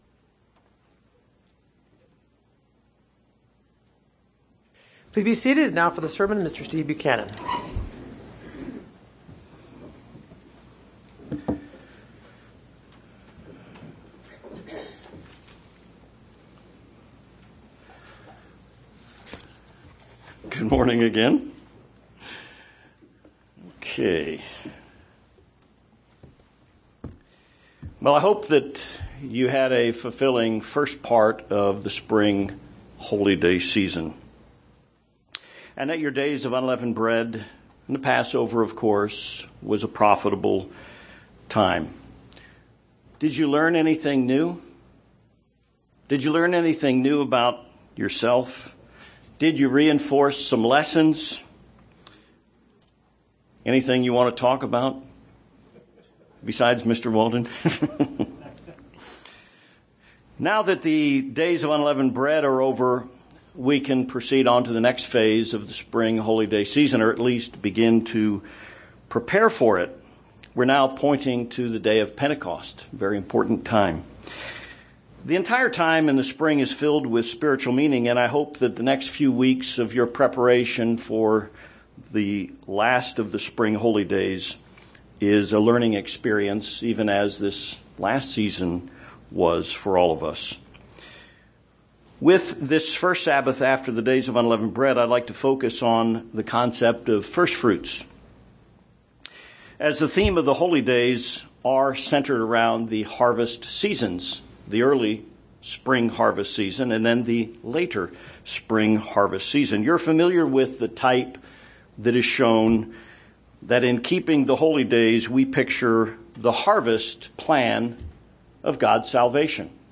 The Holy Days are based on the harvest seasons. This sermon helps evaluate the topics of Firstfruits and the Day of Pentecost with the foundation of three principles.